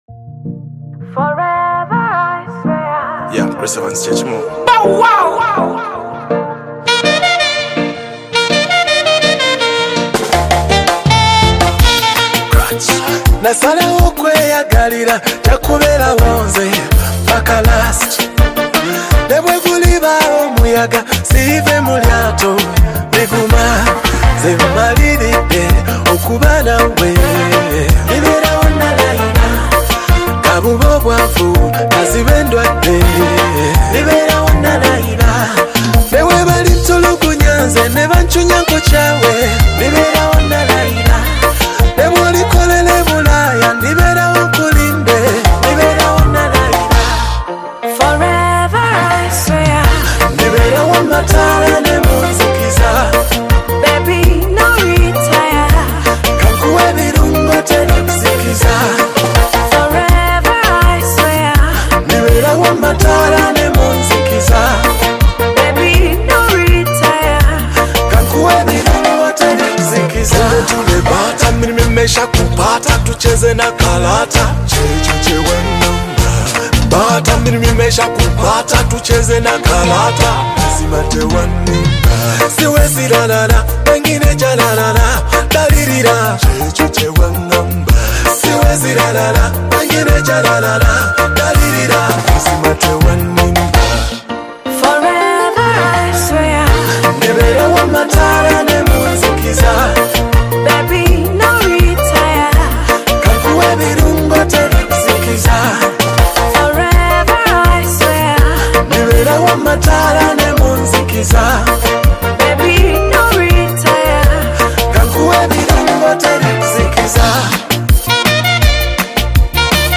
Afro Beat singer